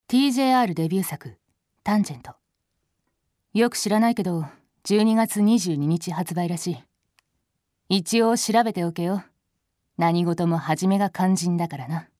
「tan.-タンジェント-」応援ボイス　-かなた編-